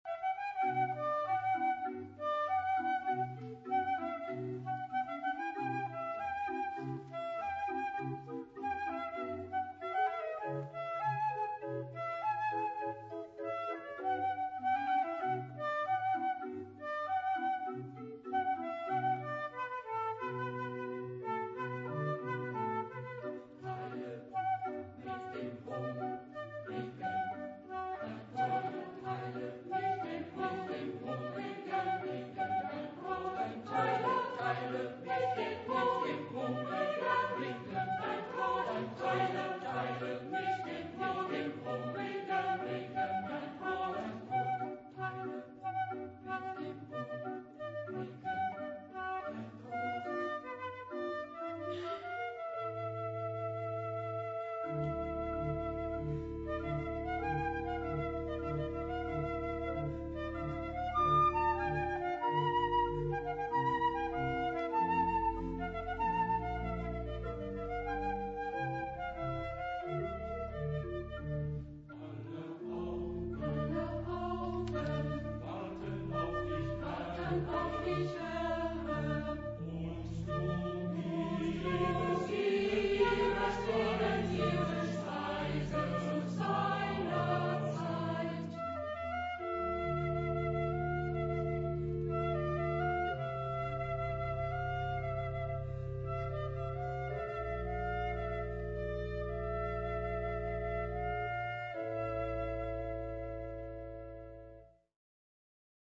Genre-Style-Form: Cantata ; Sacred
Mood of the piece: rhythmic ; lively ; andante
Type of Choir: SATB  (4 mixed voices )
Instruments: Flute (1) ; Organ (1)
Tonality: D minor